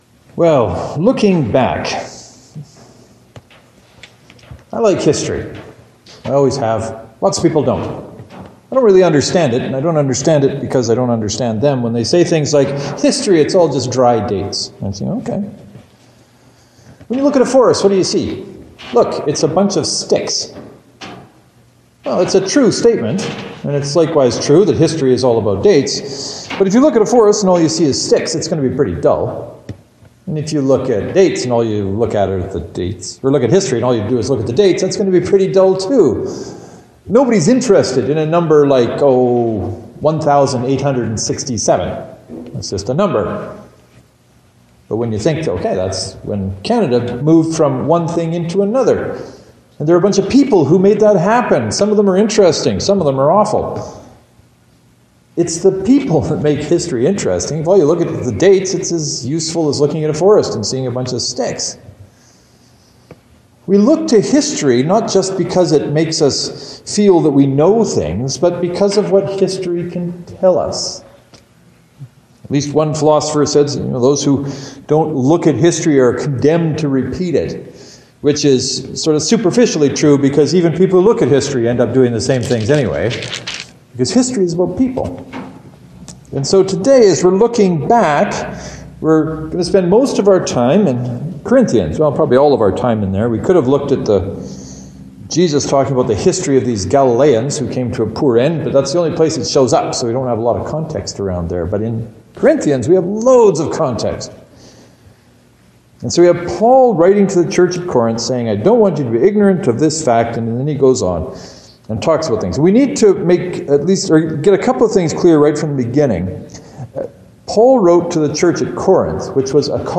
The actual sermon title is “Looking back” so how could I come up with any other blog post title.